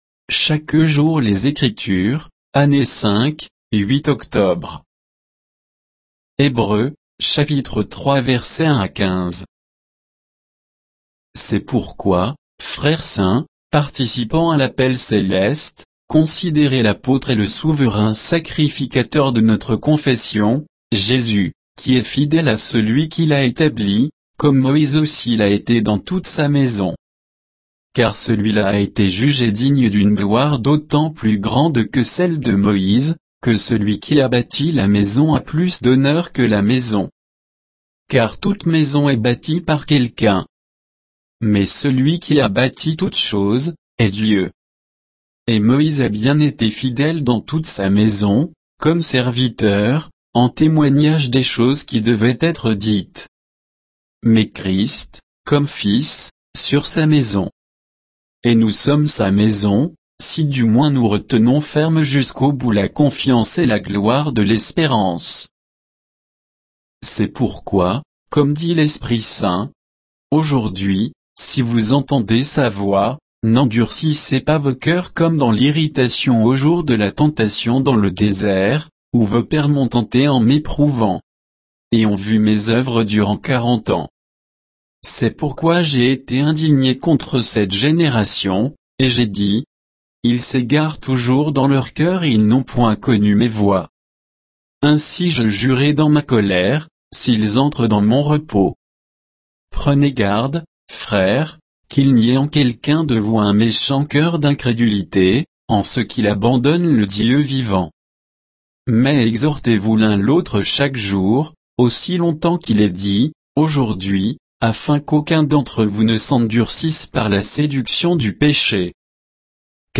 Méditation quoditienne de Chaque jour les Écritures sur Hébreux 3, 1 à 15